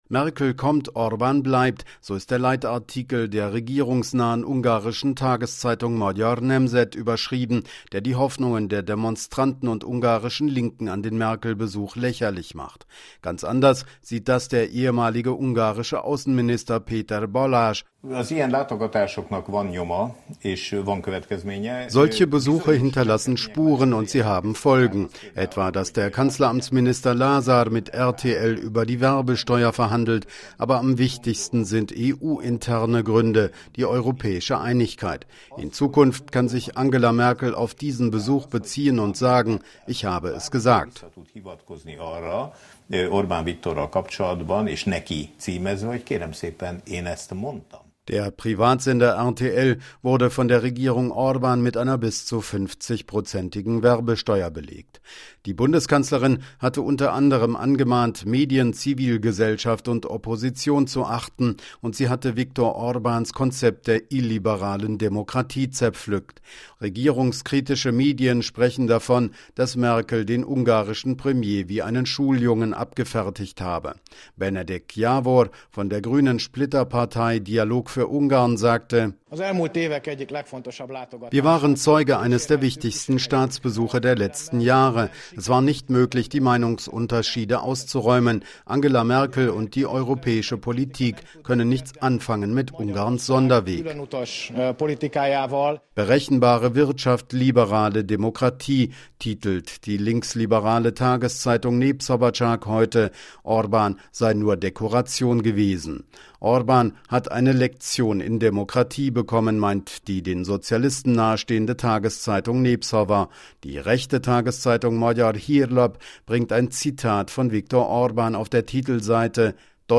berichtet aus Budapest